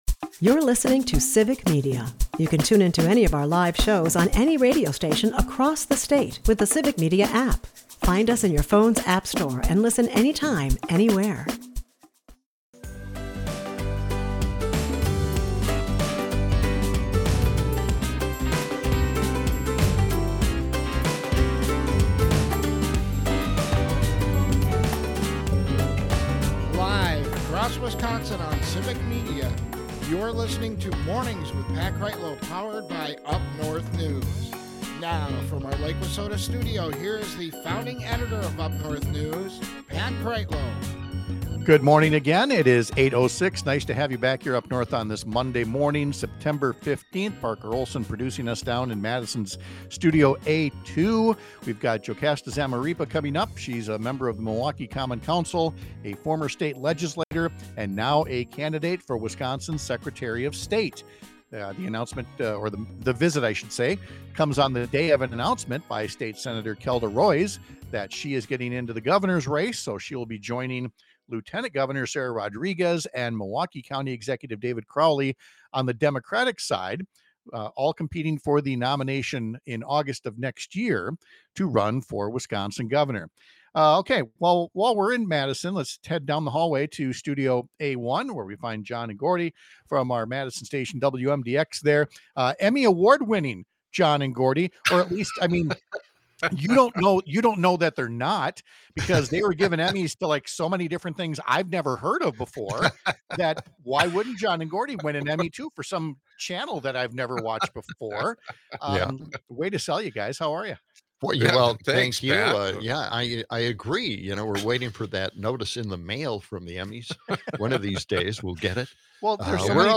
The candidate list for the 2026 election is growing again this week. We’ll talk about this morning’s entry of state Sen. Kelda Roys to the governor’s race. And we’ll talk live with Milwaukee city council member JoCasta Zamarripa, a former legislator who’s now running for Wisconsin Secretary of State.